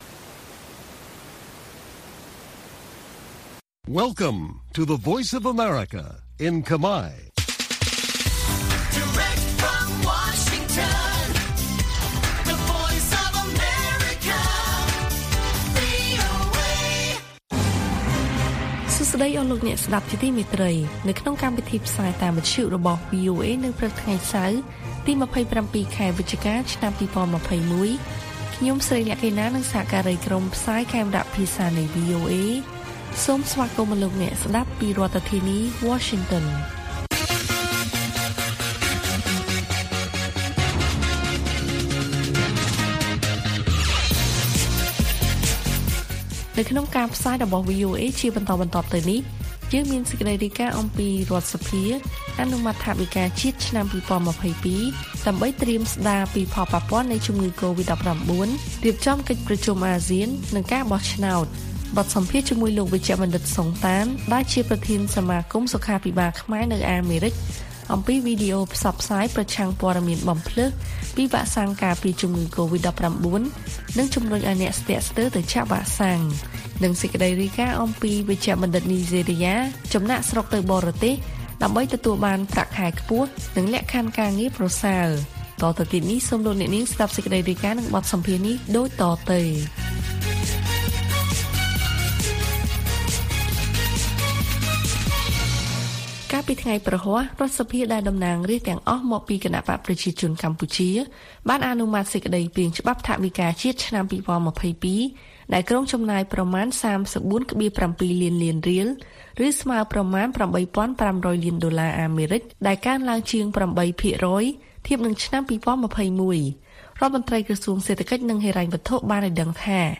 ព័ត៌មានពេលព្រឹក៖ ២៧ វិច្ឆិកា ២០២១